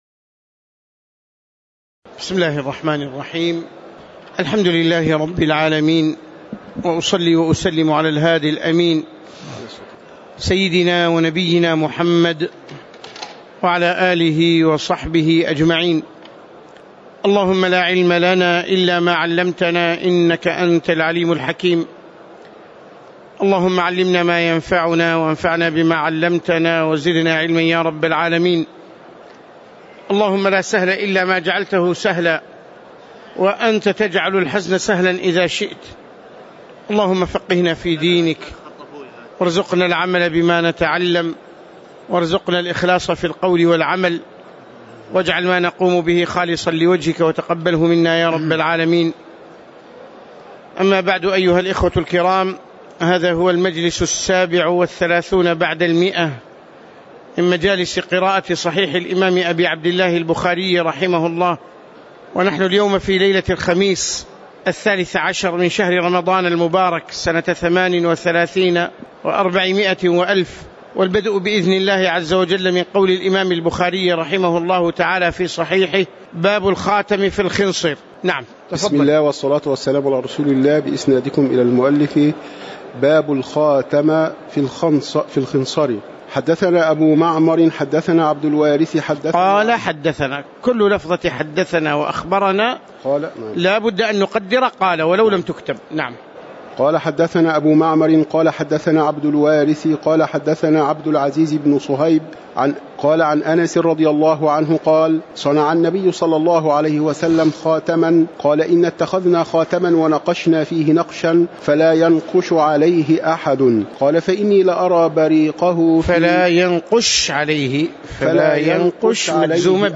تاريخ النشر ١٢ رمضان ١٤٣٨ هـ المكان: المسجد النبوي الشيخ